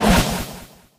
amber_ulti_throw_01.ogg